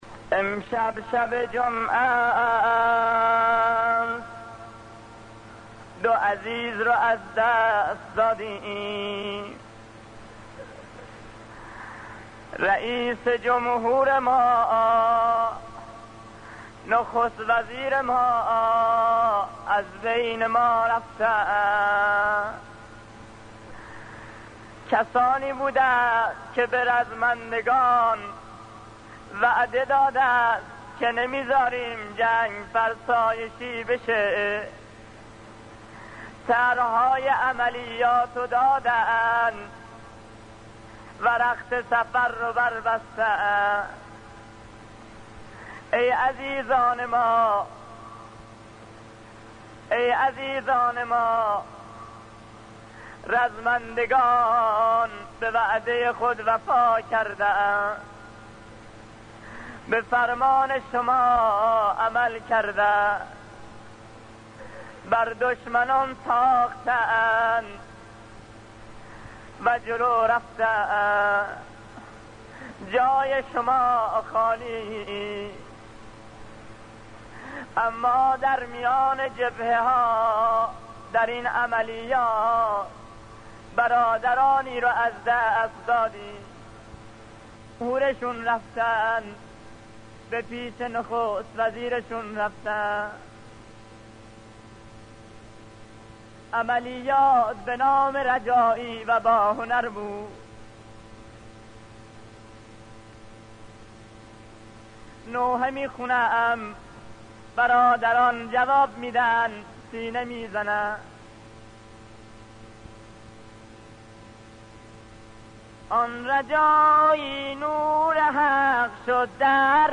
همزمان با هفته دولت و در ایام بزرگداشت شهیدان رجایی و باهنر، نوای ماندگار حاج صادق آهنگران که در همان روز‌های شهادت این دو شهید بزرگوار در رثای آنان اجرا شده بود، بازنشر شد.
مداح دفاع مقدس